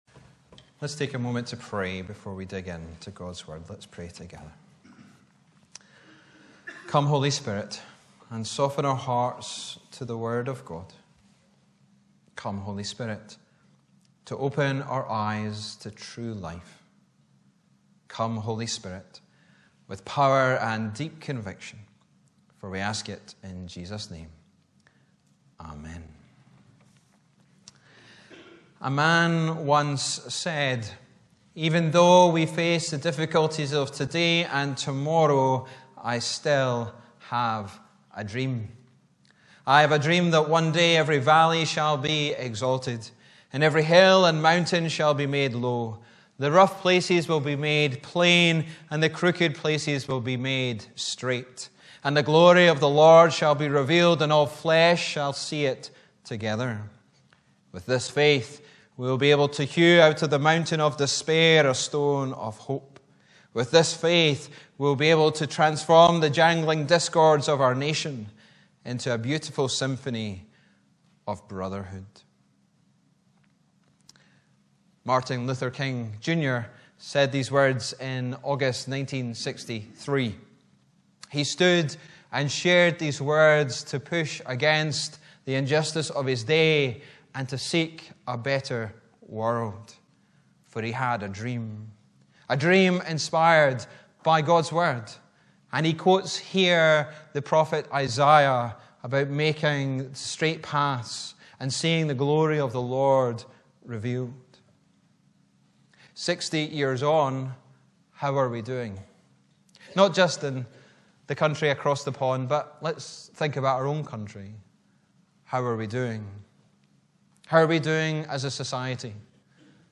Jun 02, 2024 Finding life MP3 Subscribe to podcast Notes Sermons in this Series Preached on: Sunday 2nd June 2024 The sermon text is available as subtitles in the Youtube video (the accuracy of which is not guaranteed).
Bible references: Ecclesiastes 4:1-16 Location: Brightons Parish Church